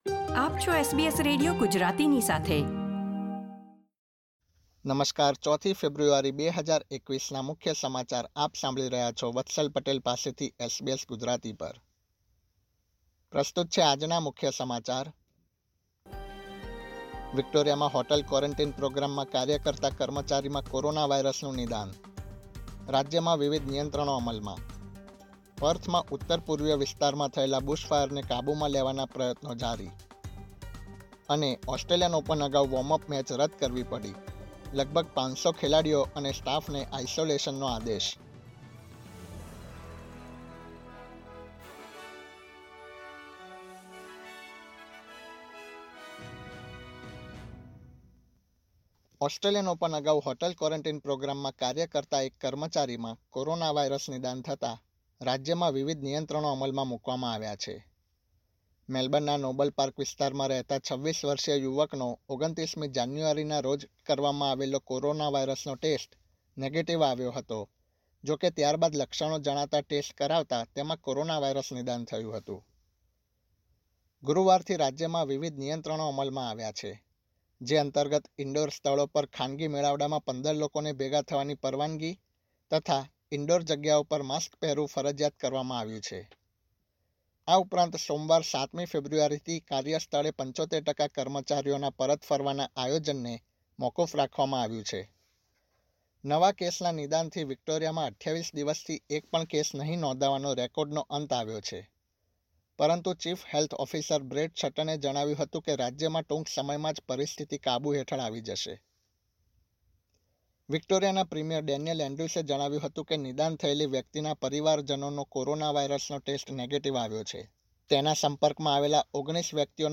SBS Gujarati News Bulletin 4 February 2021
gujarati_0402_newsbulletin.mp3